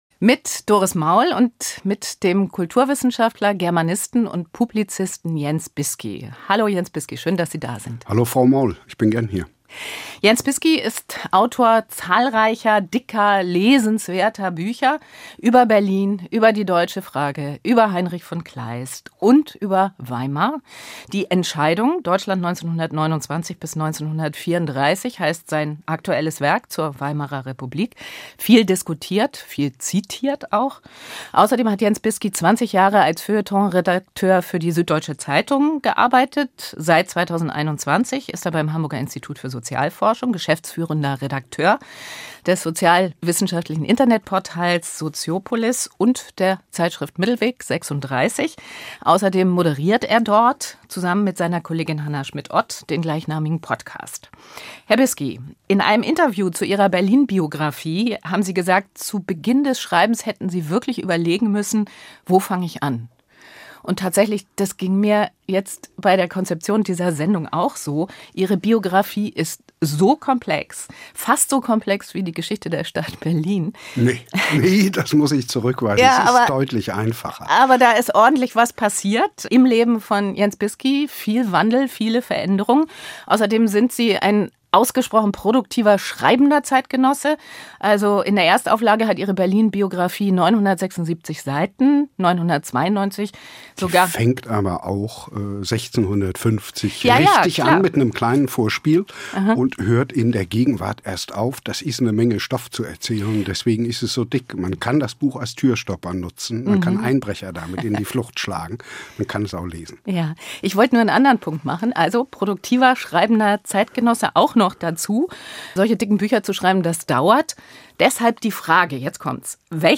im Gespräch ~ NDR Kultur - Das Gespräch